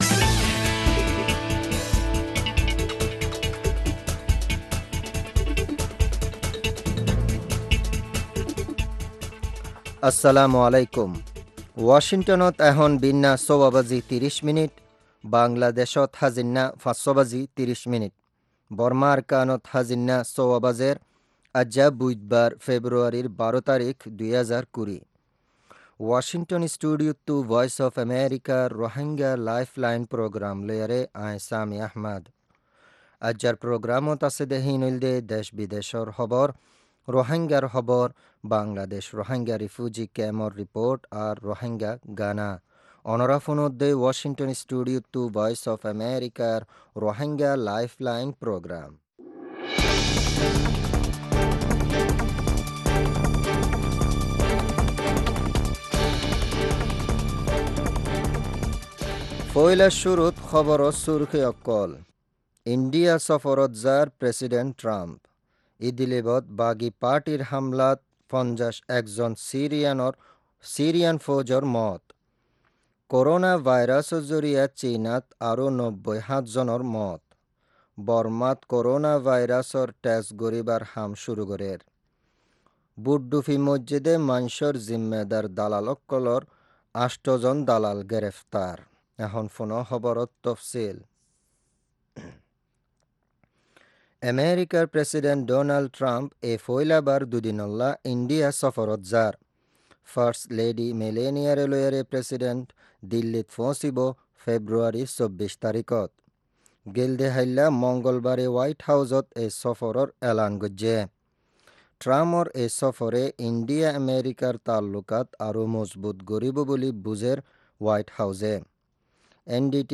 Rohingya Broadcast 02.12.2020
News headlines